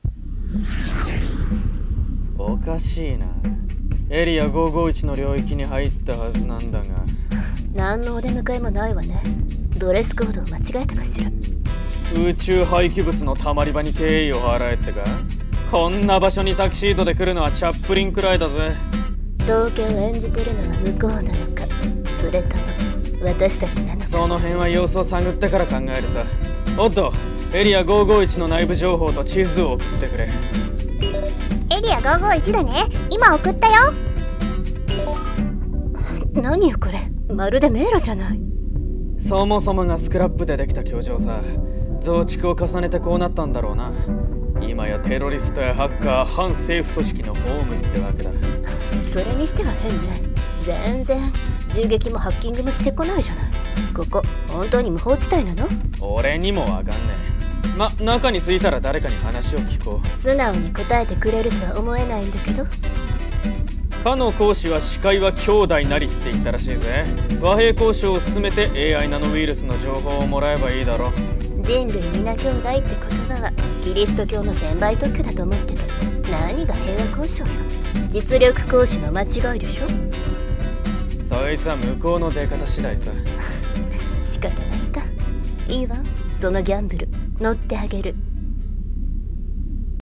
【声劇】エリア551／BOUNTY SOUL 【2人声劇】